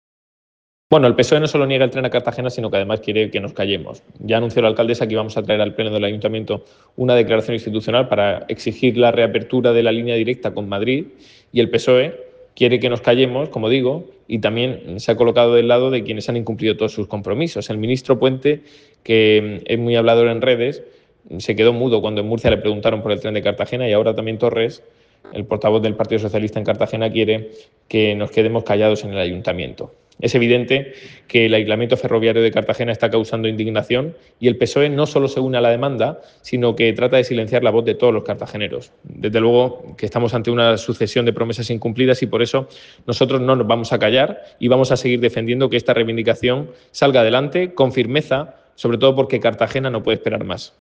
Ignacio Jáudenes en el pleno municipal del 25 de marzo